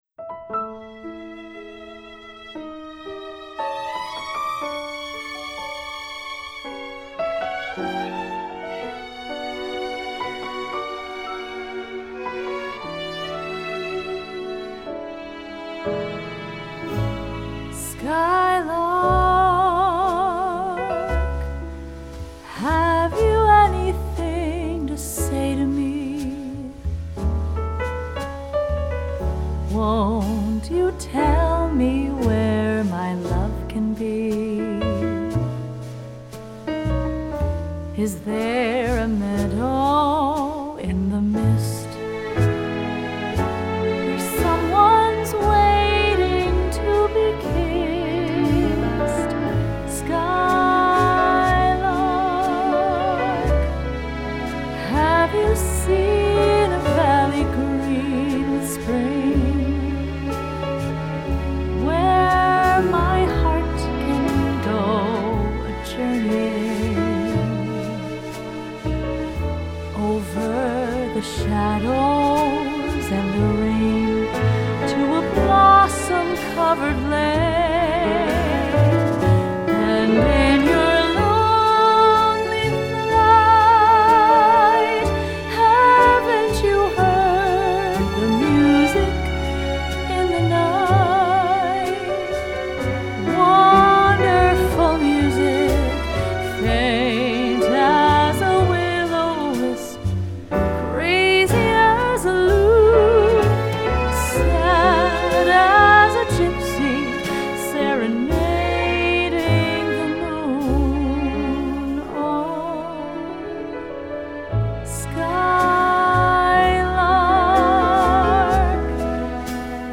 vocalist
FILE: Jazz Vox